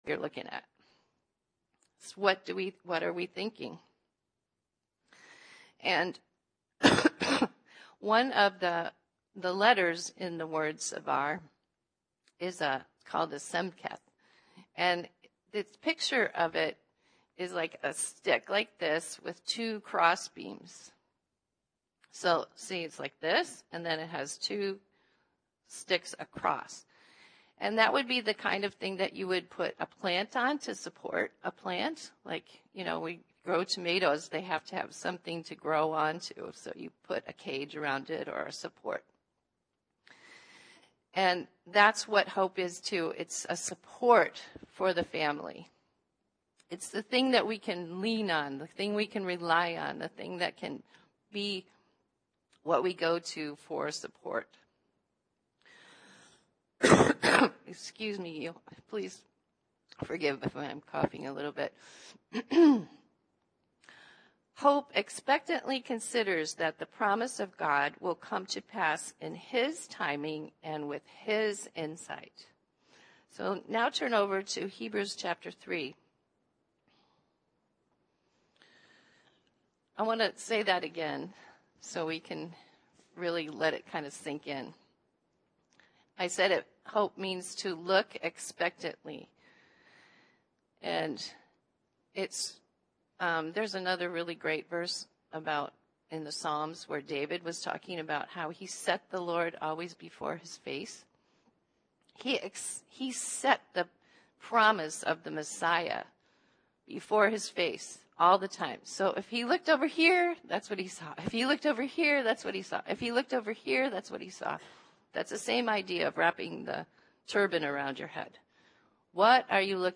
Church in San Diego California